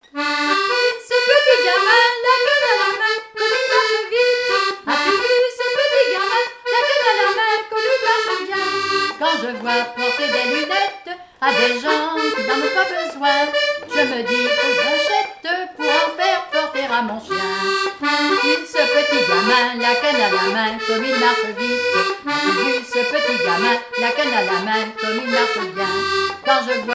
Enfantines - rondes et jeux
danse : mazurka
Quadrille de Rochetrejoux et danses populaires
Pièce musicale inédite